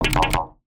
UIClick_Menu Reject Double.wav